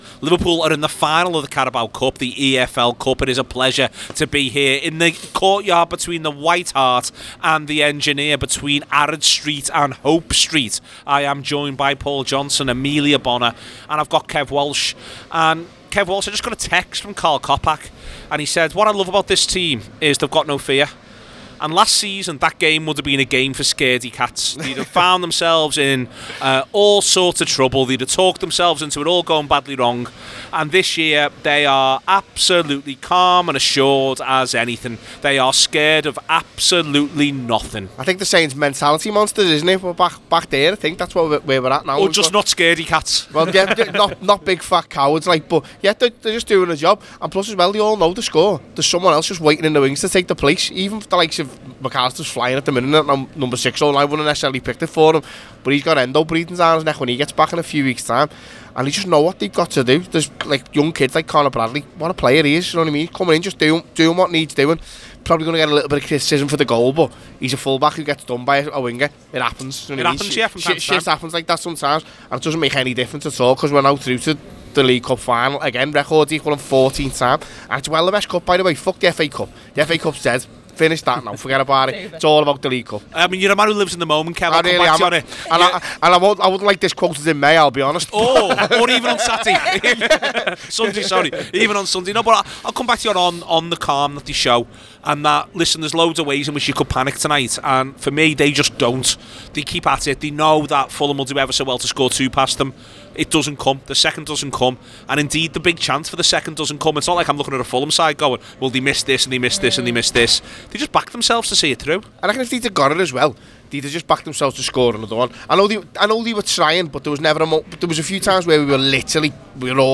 The Anfield Wrap’s post-match reaction podcast after Fulham 1 (2) Liverpool 1 (3) in the League Cup semi-final second leg at Craven Cottage.
Below is a clip from the show – subscribe to The Anfield Wrap for more reaction to Fulham 1 Liverpool 1…